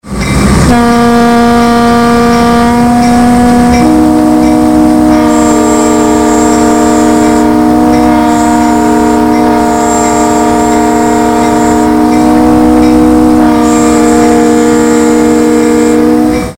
All three bells would sound when the engineer's horn valve was fully opened.
"Third generation" PM-920 horns tend to play: 247, 317, 446 Hz (approximately B, D#, A)
Horns with internal valves